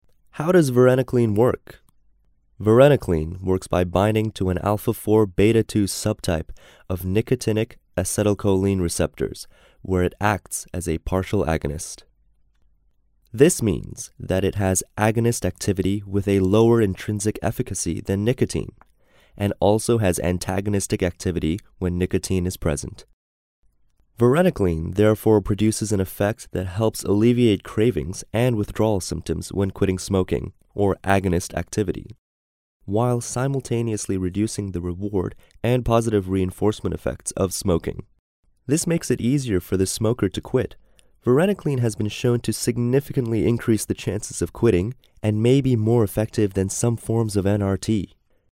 Narration audio (MP3) Contents Home Varenicline for smoking cessation How does varenicline work?